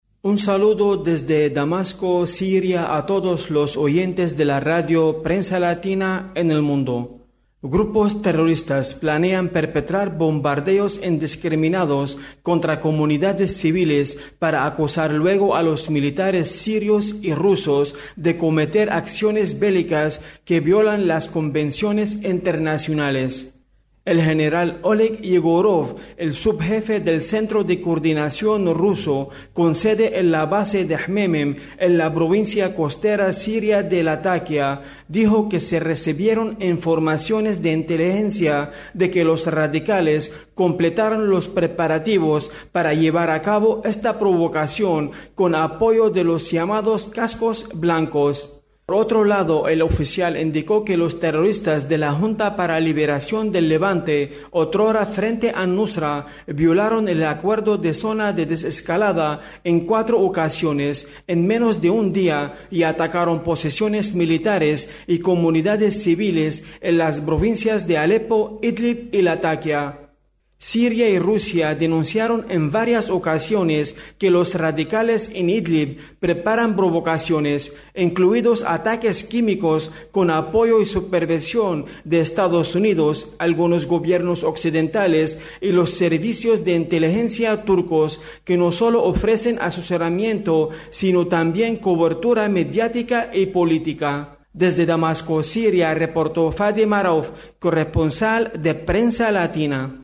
desde Damasco